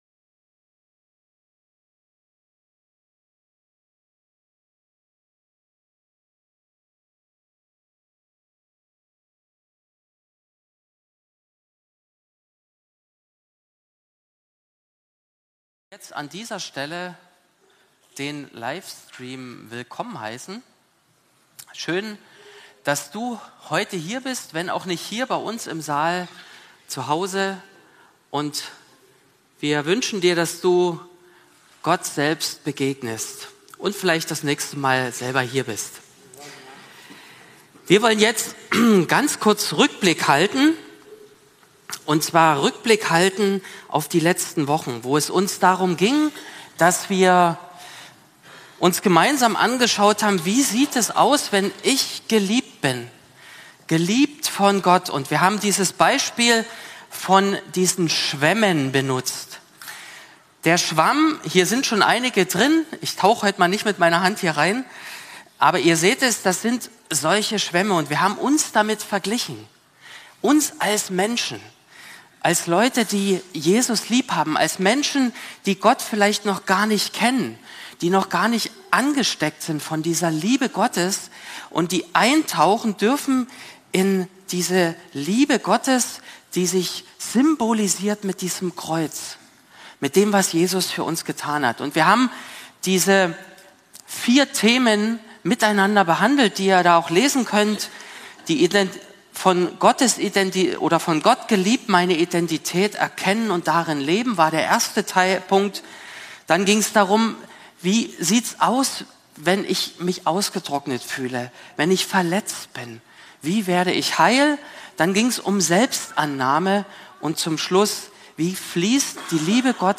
Abschluss der Predigtreihe zum Jahresmotto 2026